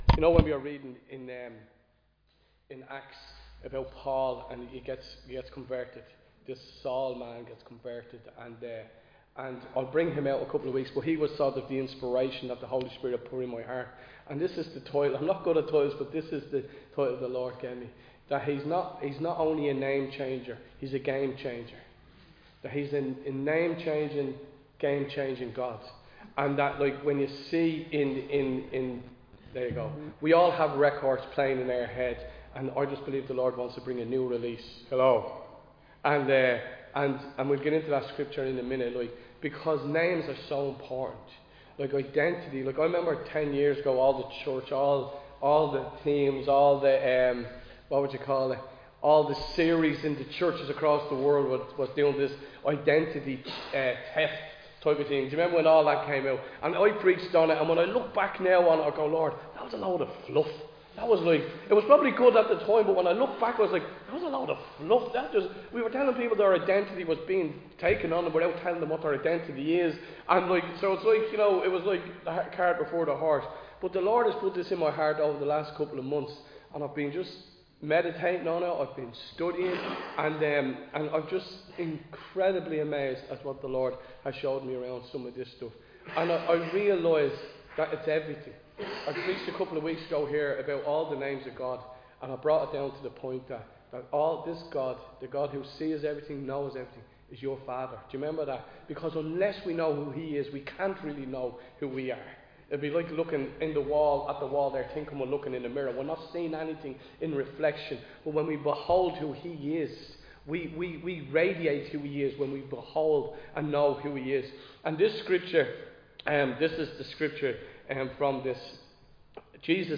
Recorded live in Liberty Church on 30 March 2025